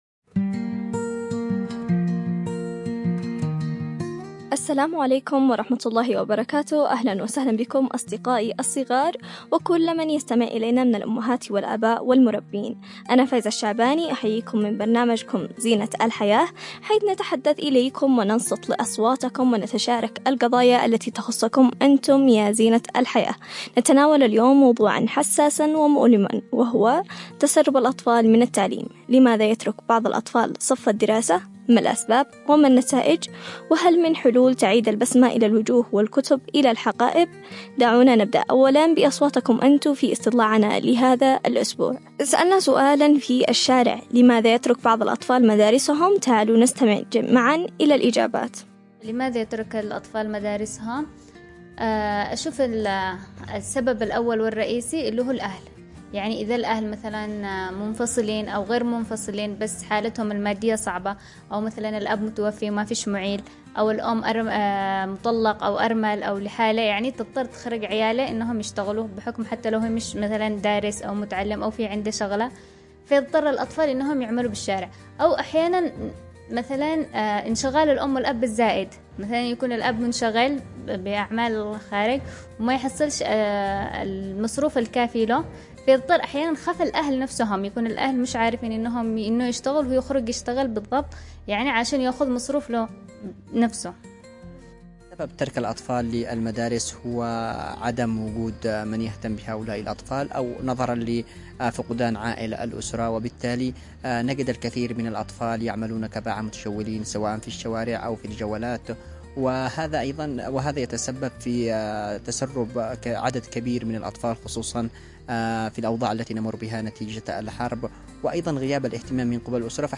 في حوار صريح حول ظاهرة تسرب الأطفال من التعليم، وأثرها العميق على الفرد والمجتمع.
على أثير إذاعة رمز